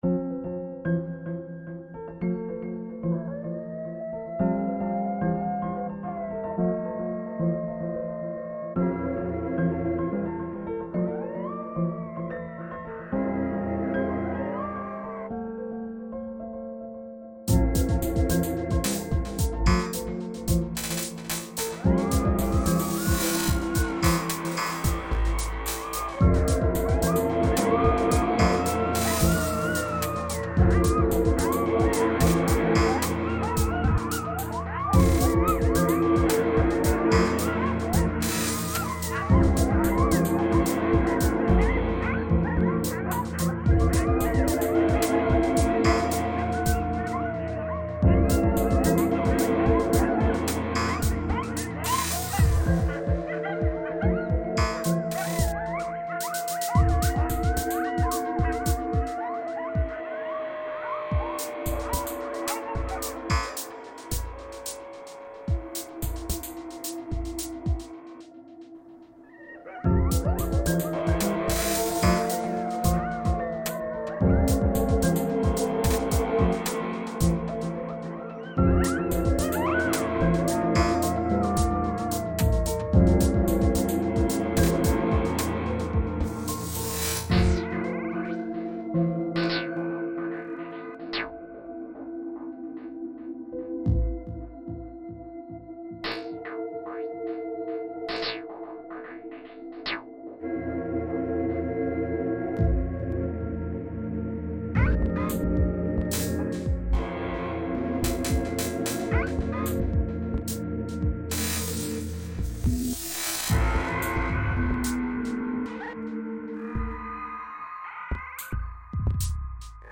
Yellowstone coyotes reimagined